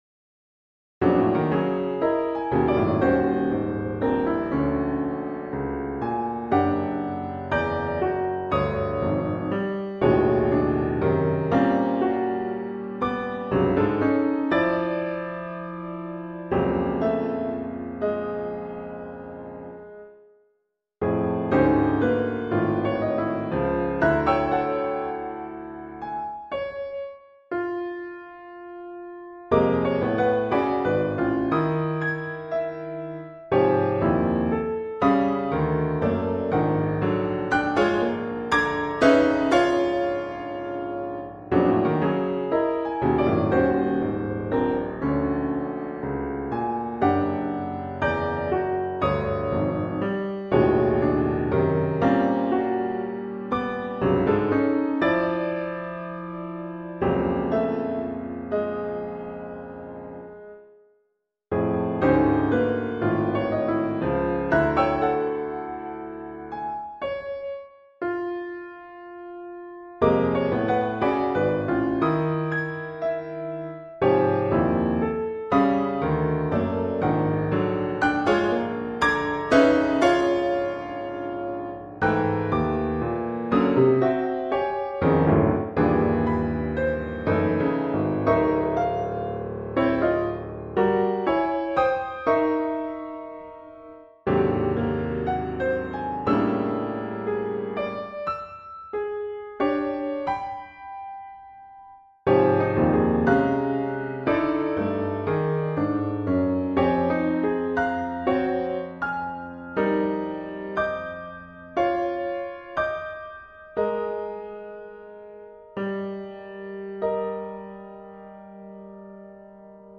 Works for automatic piano(1998)  8:27